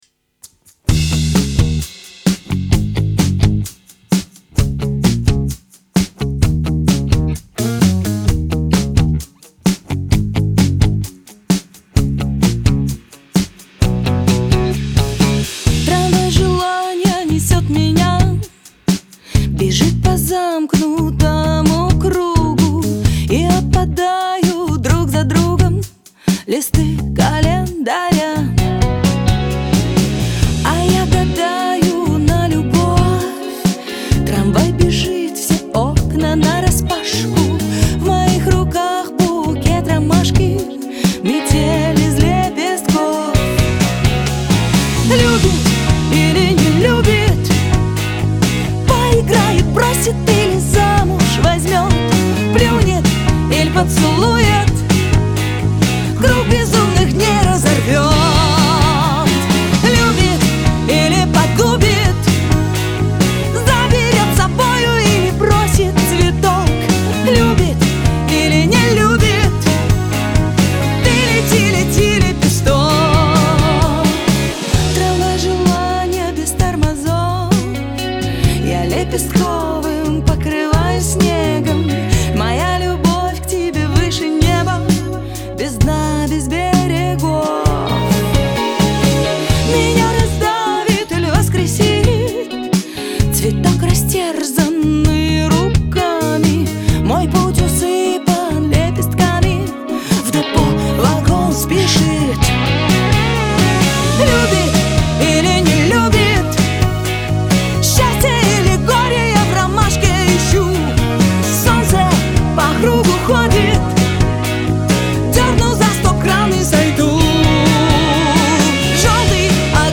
дуэт
Лирика
Веселая музыка